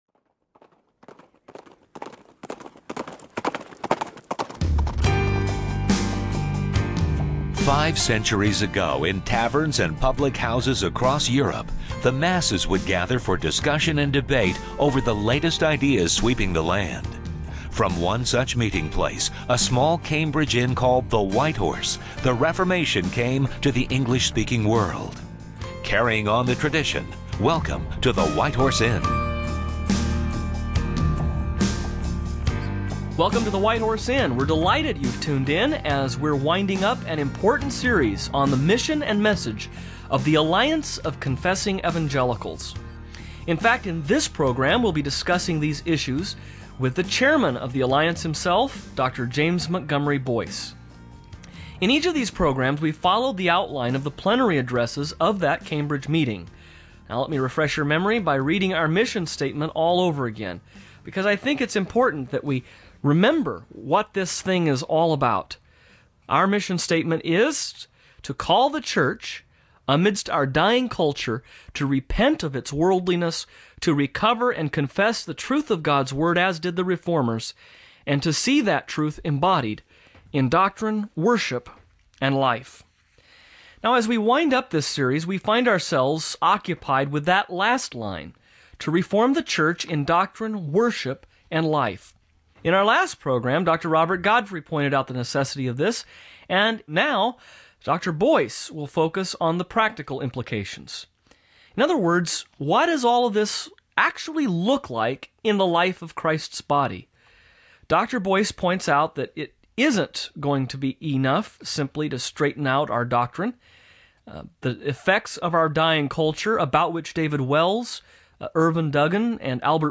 In an attempt to make churches more attractive and relevant, over the decades, many pastors effectively stopped preaching and teaching the contents of the Bible, and this has resulted in a significant decrease in basic Bible literacy among Christians across all denominations. Thirty years ago today, a diverse group of pastors and theologians met in Cambridge, Massachusetts, to address this and other challenges, and they produced an important document called The Cambridge Declaration. This program features audio from the 1996 meeting featuring R.C. Sproul